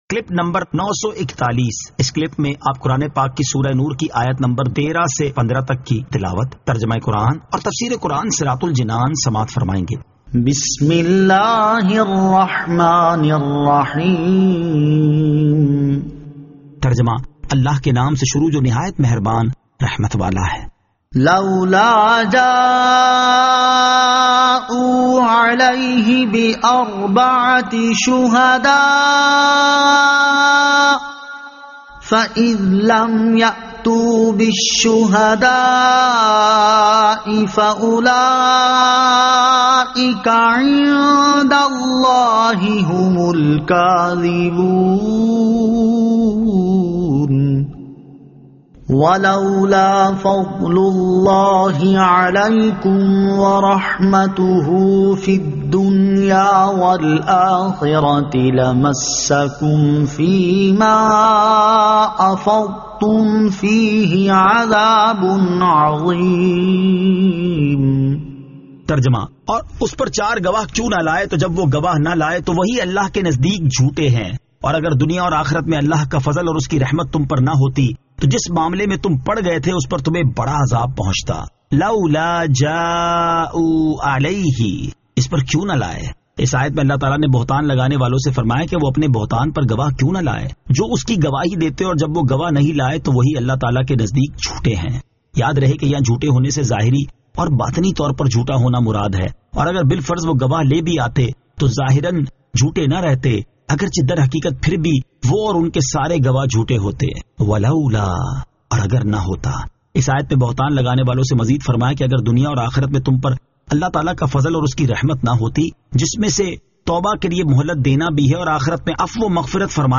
Surah An-Nur 13 To 15 Tilawat , Tarjama , Tafseer